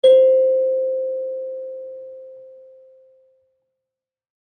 kalimba1_circleskin-C4-ff.wav